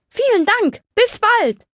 Fallout 3: Audiodialoge